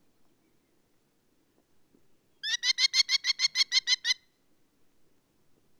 Merlin Ruf
Merlin-Ruf-Voegel-in-Europa.wav